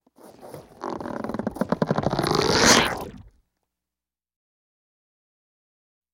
Rubber Stretches; Rubber Processed Stretching And Bending. - Cartoon, Stretchy Rubber